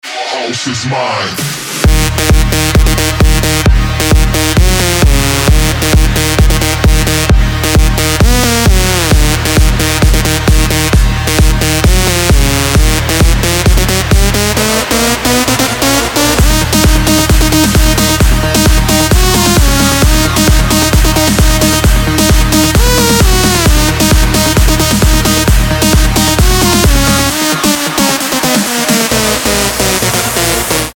• Качество: 320, Stereo
electro house